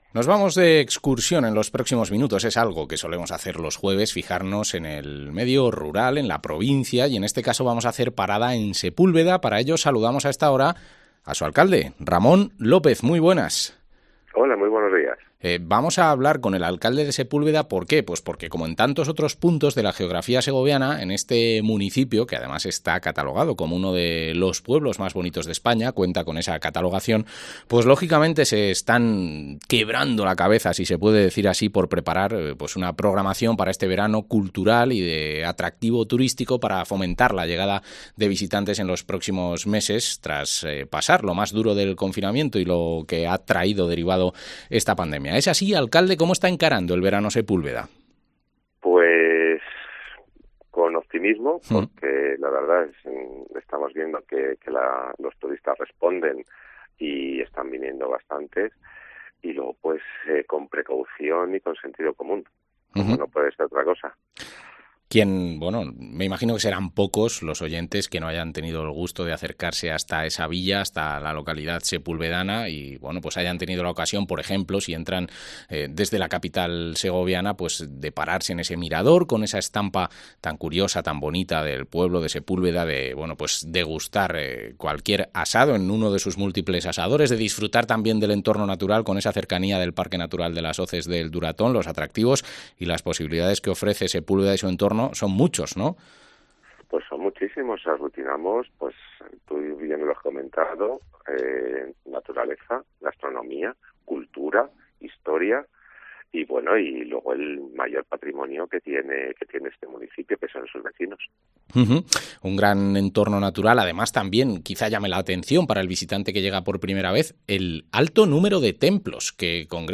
Entrevista al alcalde de Sepúlveda, Ramón López